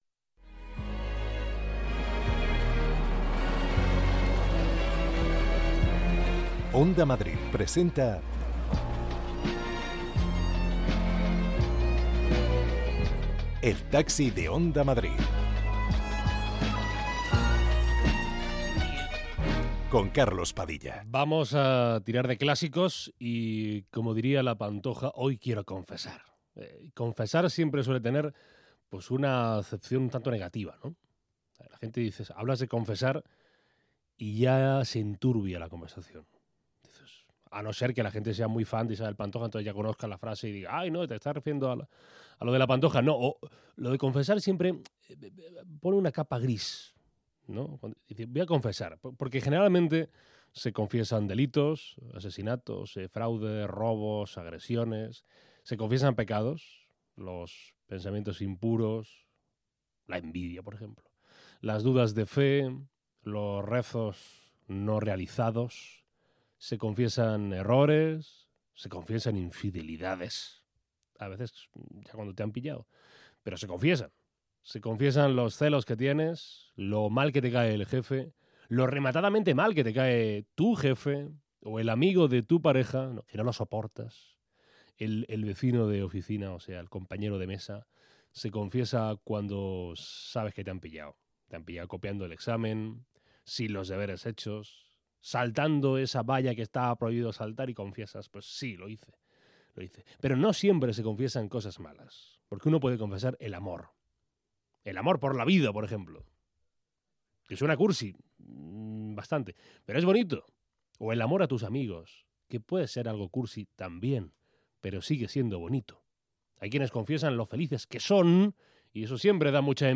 Conversaciones para escapar del ruido. Recorremos Madrid con los viajeros más diversos del mundo cultural, político, social, periodístico de España...